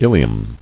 [il·e·um]